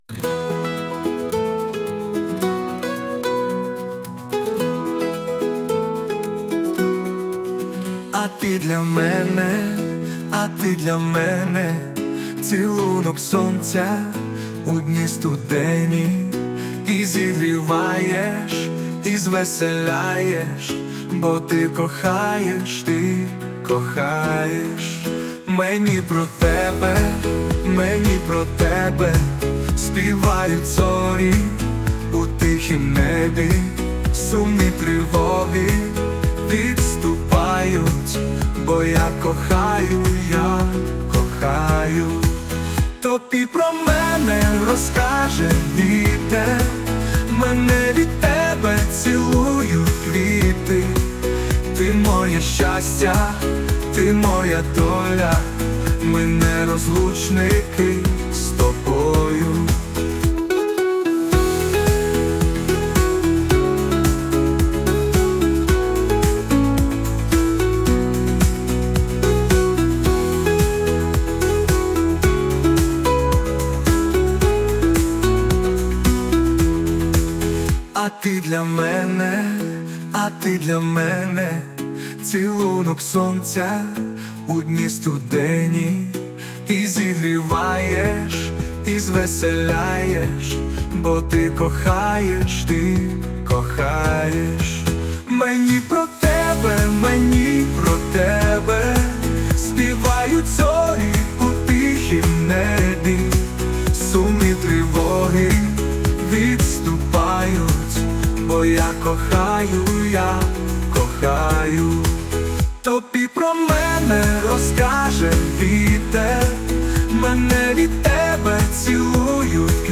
Музична композиція - ШІ
ТИП: Пісня
СТИЛЬОВІ ЖАНРИ: Ліричний